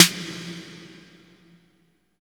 80 VRB SN2-L.wav